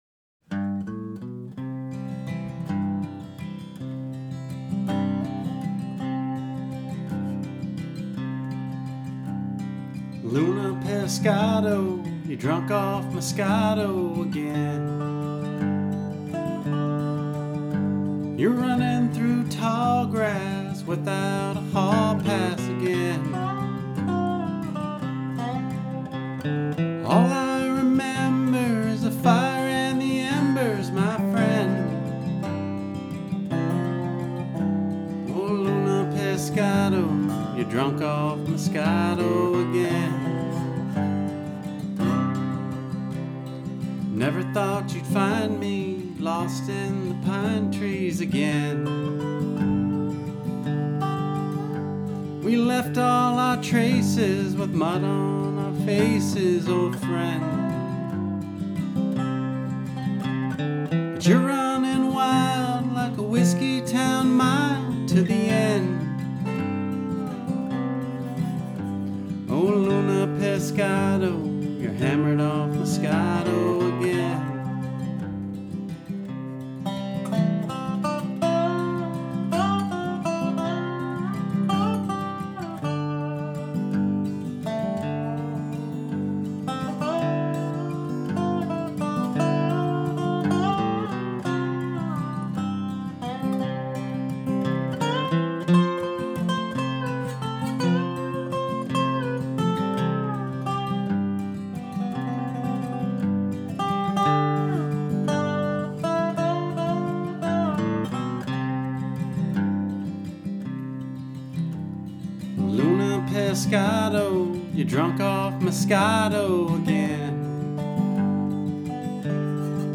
Live Demo cleanup - mix suggestions?
The song is recorded with a single LDC condenser (AT 4033a) centered about chin level, with the 2 performers angled toward it bluegrass style. There are also 2 Rode pencil condensers set up XY stereo about 8 feet or so in front of the performers.
The room is very lively (boomy)...rectangular shape and wood all around.
I did some basic EQ to clean up some boom and shrill, a tad compression to balance it.
I keep hearing one little odd delay on the first couple words.
The only thing that's not really working for me is that the boxiness of the room is very audible in the vocal.